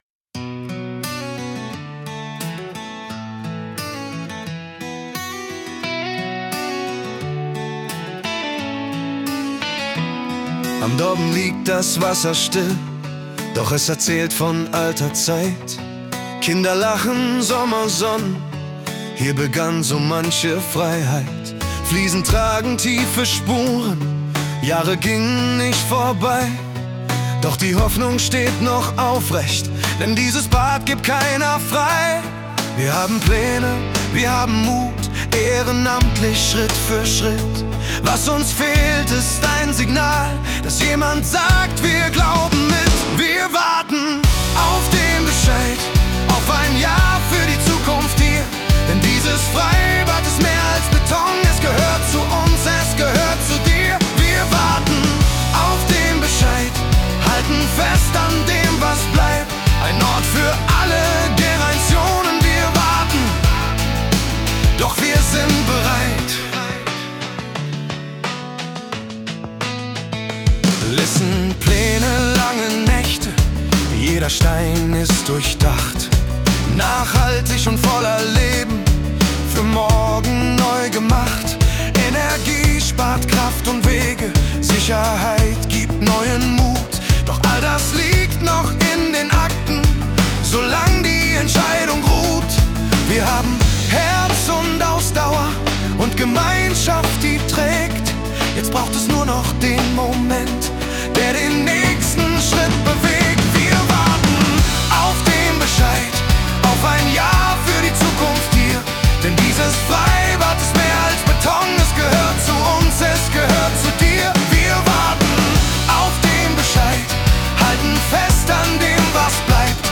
Zu der ohnehin fortlaufenden Berichterstattung haben wir unsere Situation in einem Liedtext formuliert und mittels KI singen lassen.